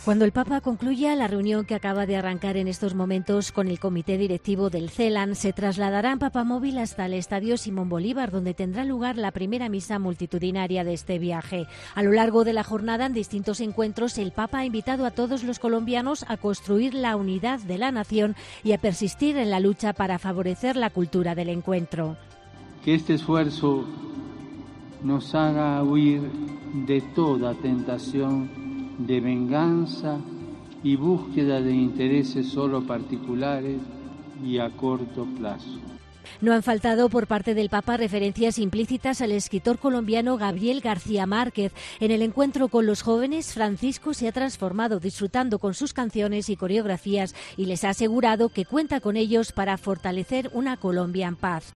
El Papa en la misa en Dacca en la que ordenó a 16 nuevos sacerdotes